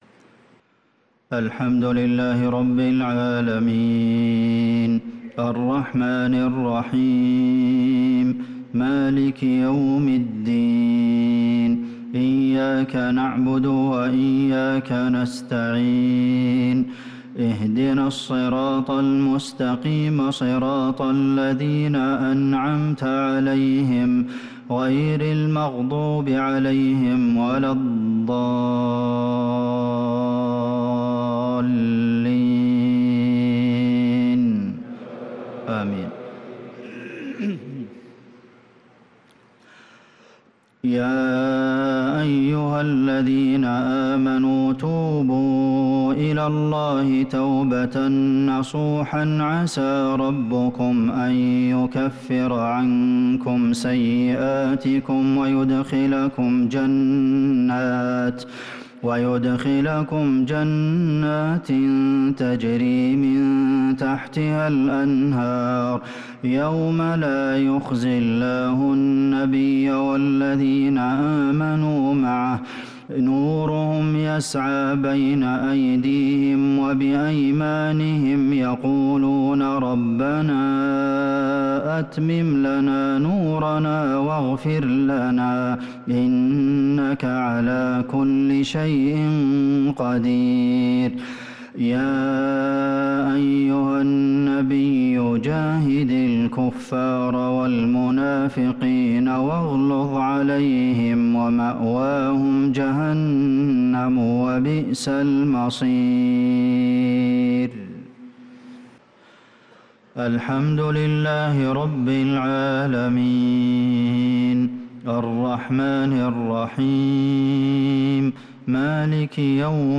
عشاء الاثنين 6-3-1446هـ خواتيم سورة التحريم | Isha Prayer from Surat al-tahrim 9-9-2024 > 1446 🕌 > الفروض - تلاوات الحرمين